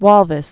(wôlvĭs)